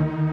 Freq-lead12.ogg